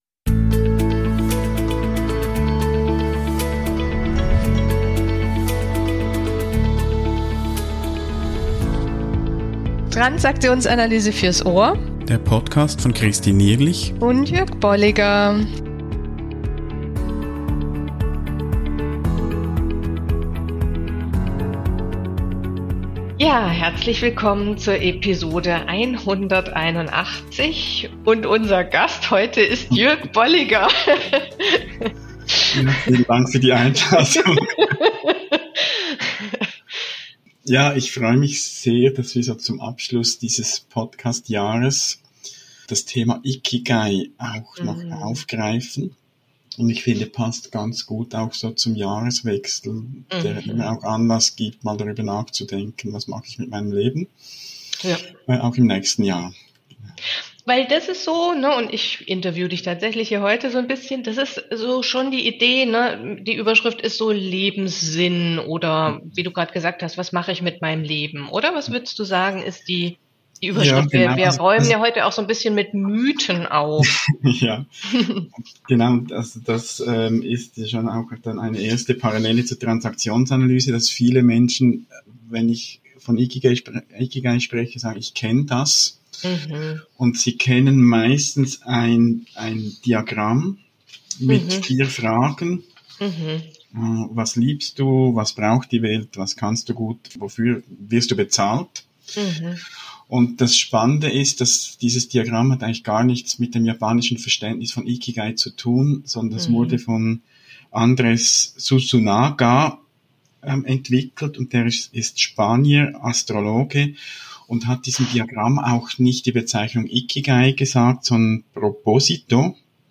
Gespräche über Modelle und Konzepte der Transaktionsanalyse